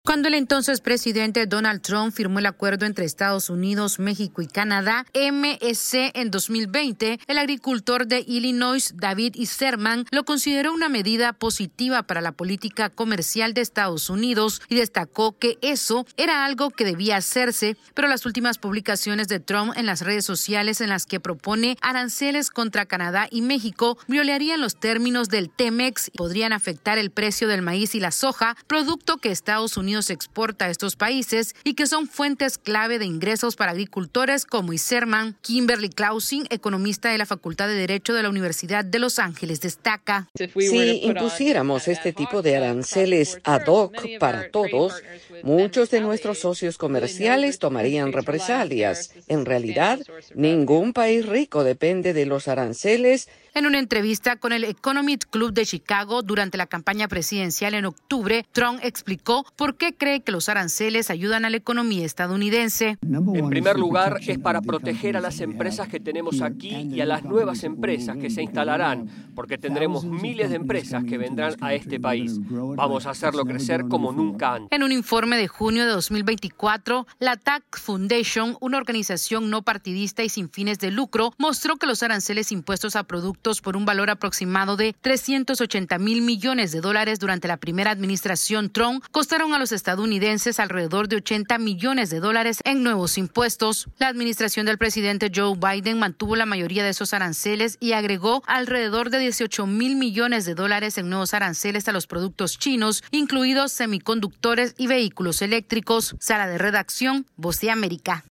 El presidente electo Donald Trump continúa insistiendo en su amenaza de imponer aranceles a Canadá, China y México, tres de los principales socios comerciales de Estados Unidos. Esta es una actualización de nuestra Sala de Redacción....